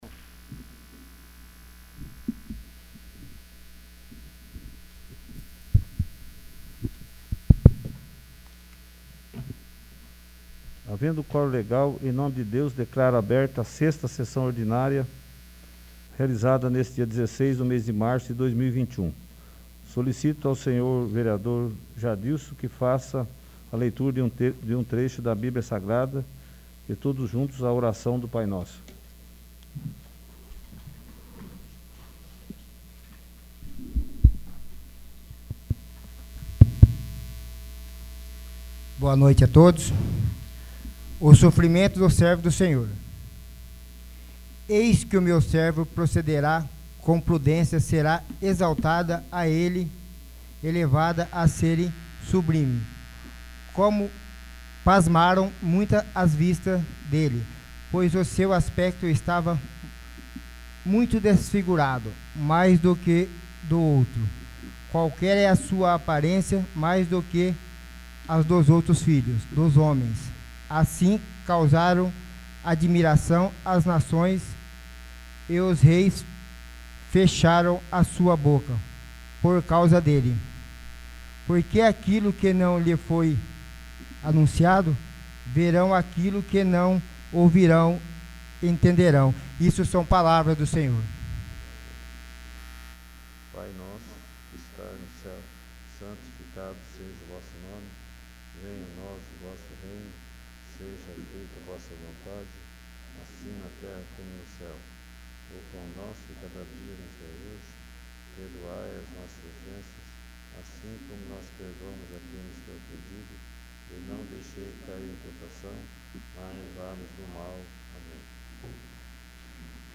6º. Sessão Ordinária